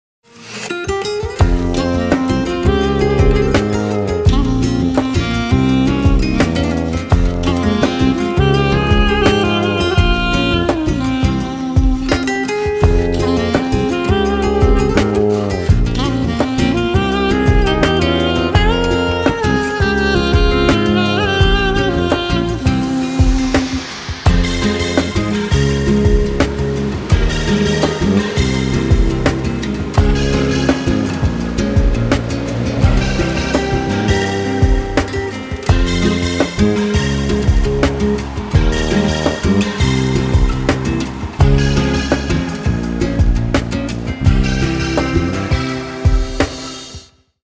Мелодия гитары и саксофона для вашего мобильного телефона.)